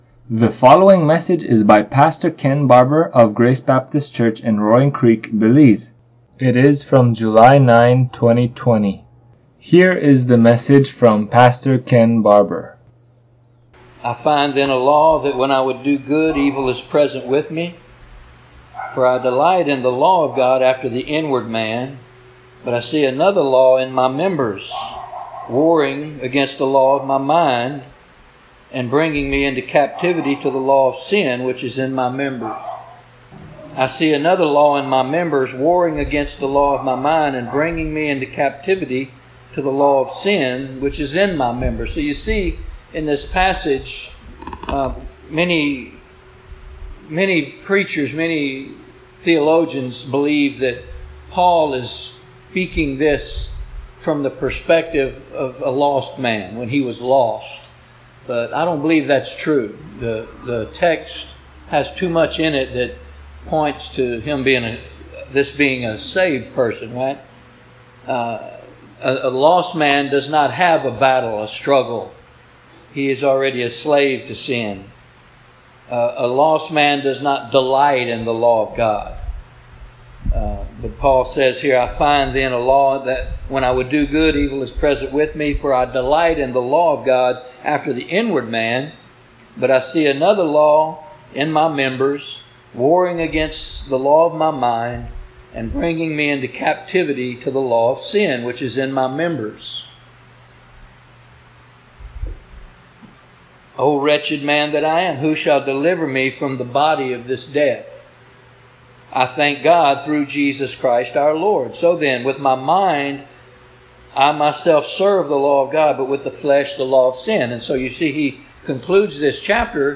Romans 8:1 Service Type: Thursday Evening Topics